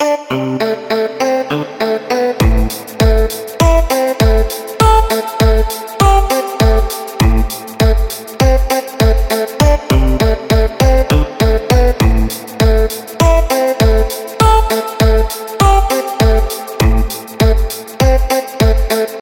Electronic
без слов
Просто позитивный электронный мотив